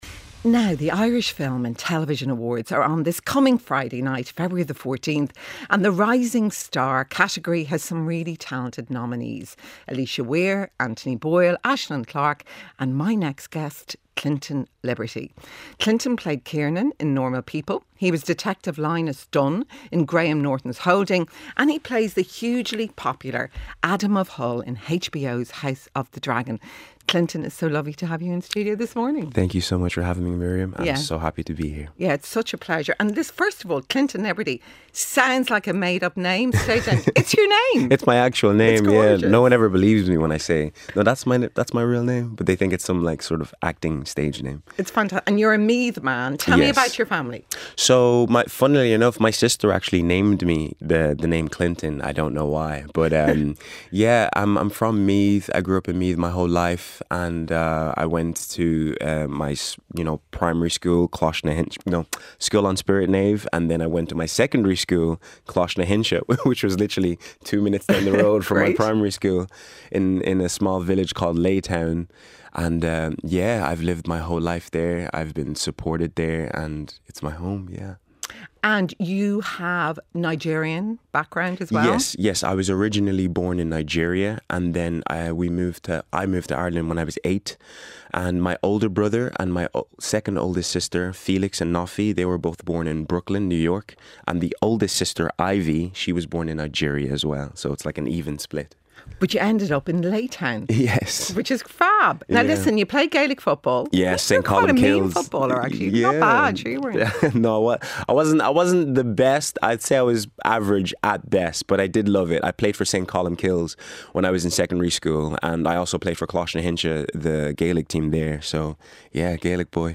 Miriam O'Callaghan presents an all-talking, all-singing, all-human-life-is-here show.